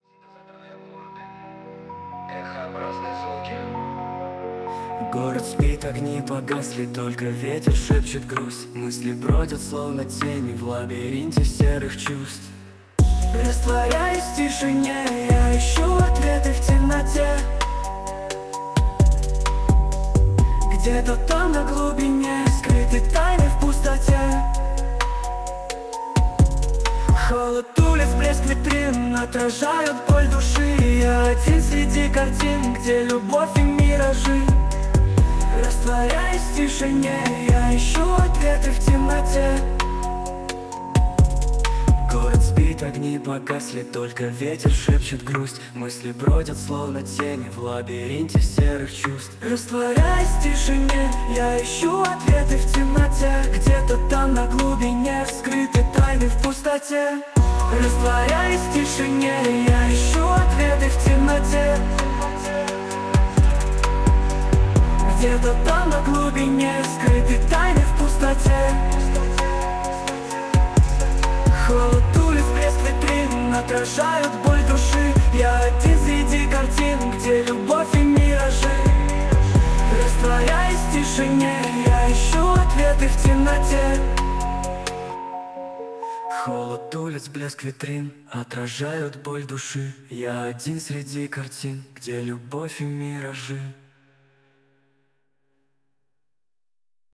Жанр: Ambient